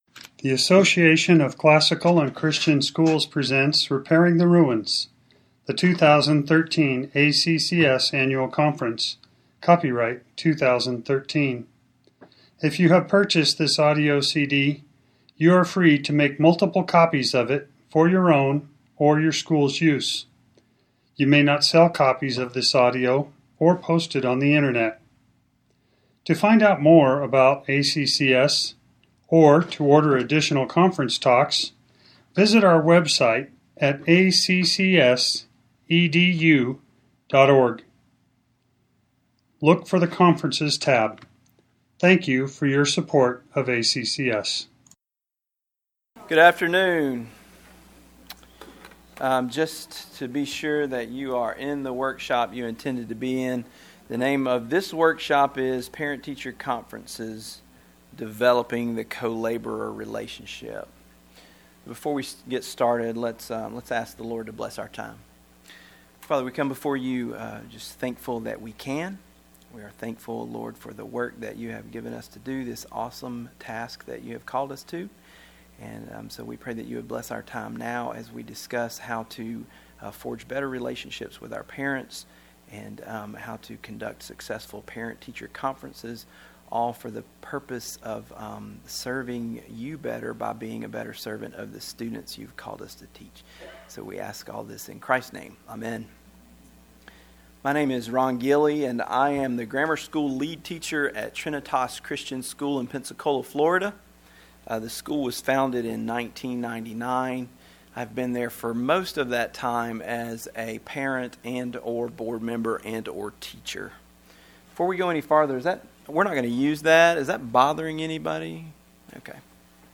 2013 Foundations Talk | 1:07:46 | All Grade Levels
Jan 19, 2019 | All Grade Levels, Conference Talks, Foundations Talk, Library, Media_Audio | 0 comments